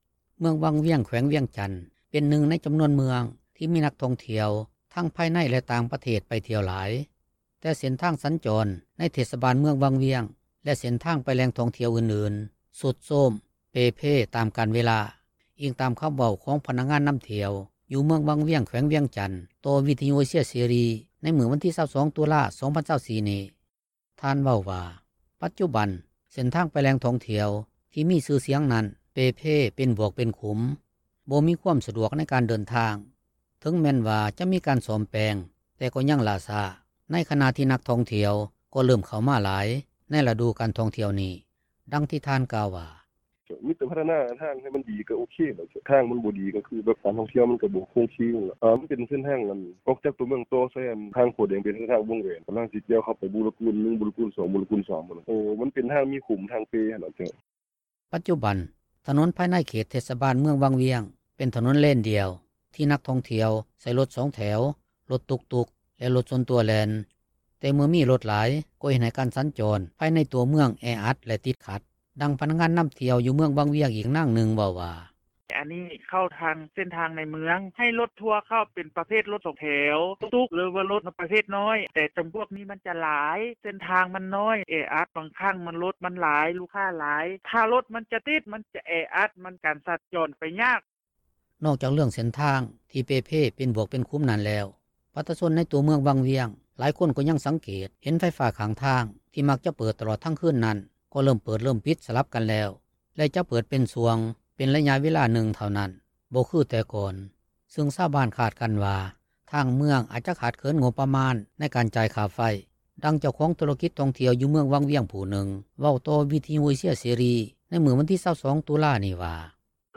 ປັດຈຸບັນ ຖະໜົນພາຍໃນເຂດເທດສະບານເມືອງວັງວຽງ ເປັນຖະໜົນ ພຽງເລນດຽວ ທີ່ນັກທ່ອງທ່ຽວ ໃຊ້ລົດ 2 ແຖວ ລົດຕຸ໋ກໆ ແລະລົດສ່ວນຕົວແລ່ນ. ແຕ່ເມື່ອມີລົດມາຫລາຍ ກໍເຮັດໃຫ້ການສັນຈອນພາຍໃນຕົວເມືອງ ແອອັດແລະຕິດຂັດ, ດັ່ງພະນັກງານນໍາທ່ຽວຢູ່ເມືອງວັງວຽງ ອີກນາງນຶ່ງ ເວົ້າວ່າ: